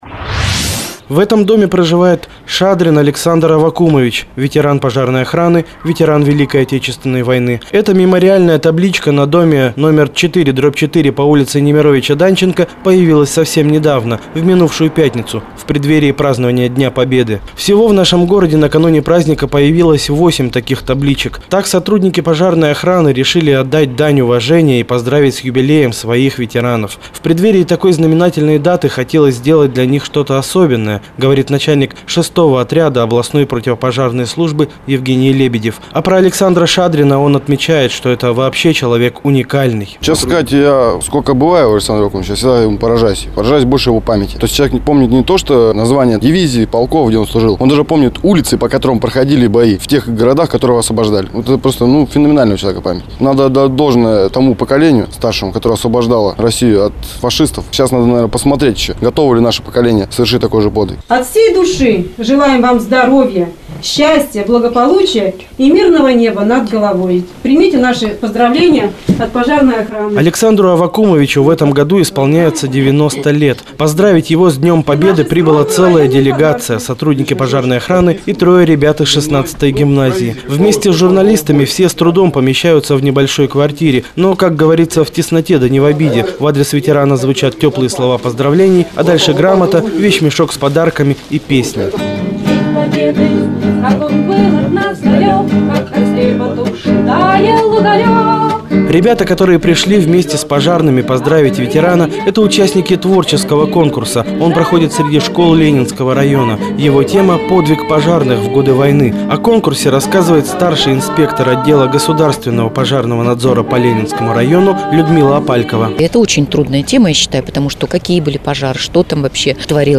Мои репортажи, вышедшие в эфир Радио "Городская волна"